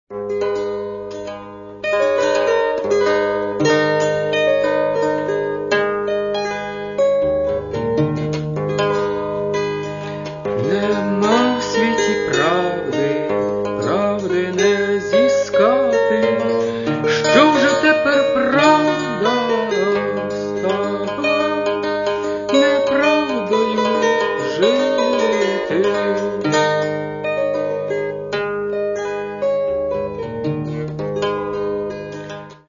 Catalogue -> Folk -> Bandura, Kobza etc